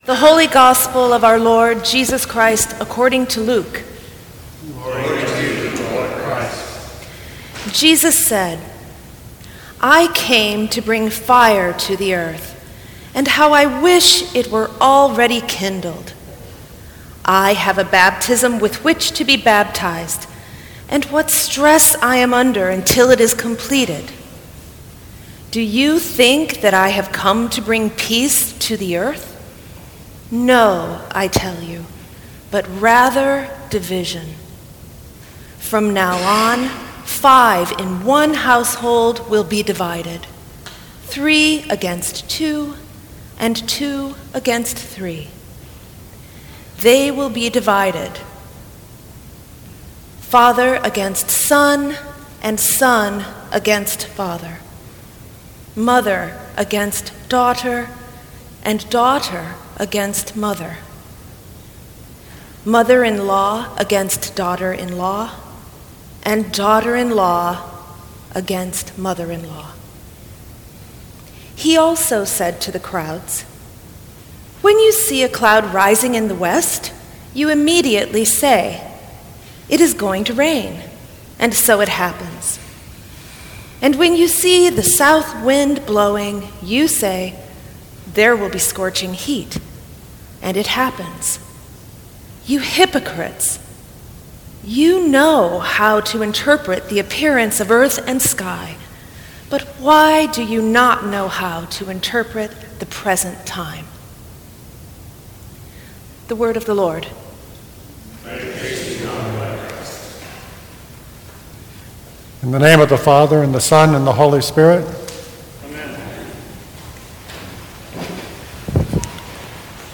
Sermons from St. Cross Episcopal Church Thirteenth Sunday after Pentecost Aug 14 2016 | 00:12:50 1x 00:00 / 00:12:50 Subscribe Share Apple Podcasts Spotify Overcast RSS Feed Share Link Embed